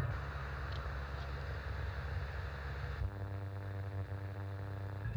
Mir ist gerade aufgefallen, dass das nicht direkt was mit den Lautsprechern, sondern mehr mit dem über das Smartphone laufende Radio zu tun hat. Die Qualität ist schlecht (aufgenommen über das interne Mikrofon des Laptops) aber fürs Rauschen sollte es reichen. In der Mitte der Datei (besonders deutlich erkennbar bei Darstellung als Spektrogramm bspw. in Audacity) erkennt man einen Wechsel der Tonhöhe bzw. der „Tonfarbe“ des Rauschens, da wird dann zurück gelesen (dieses File stammt vom funktionierenden Programm, beim nicht-funktionierenden fehlt der zweite Teil einfach komplett).